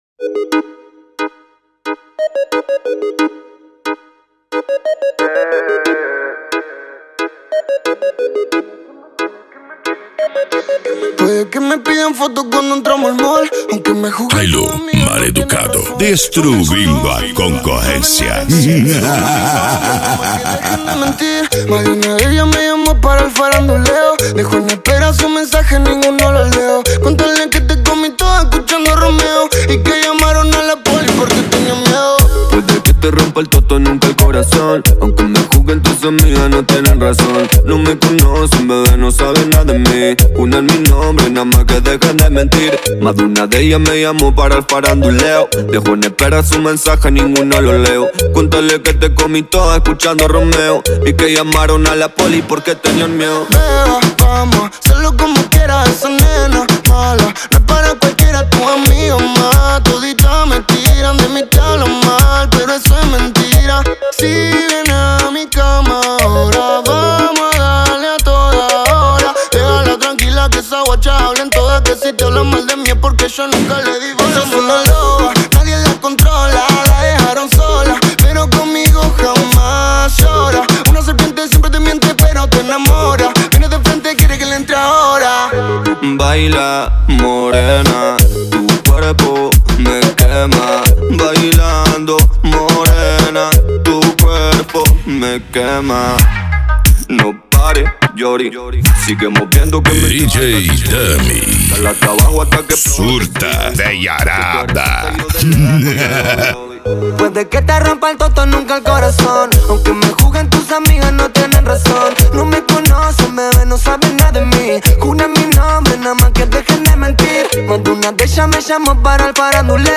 Arrocha
Pagode
Reggaeton